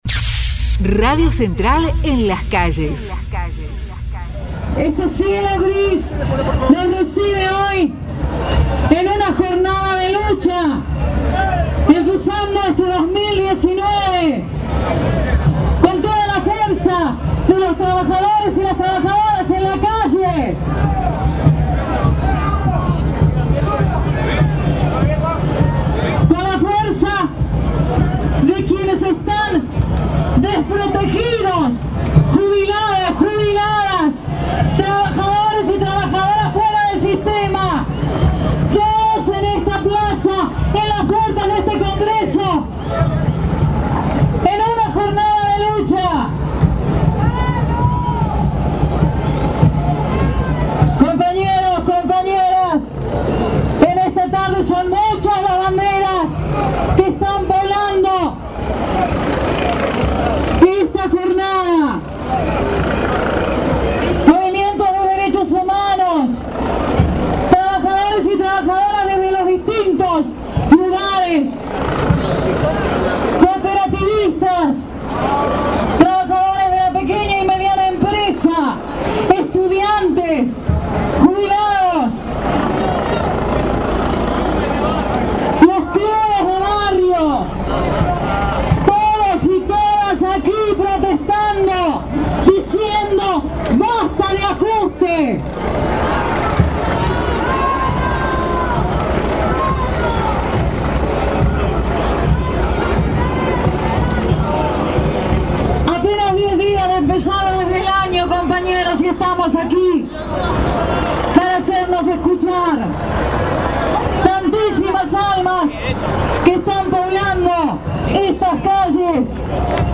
MARCHA DE ANTORCHAS: BASTA DE TARIFAZOS // lectura del documento político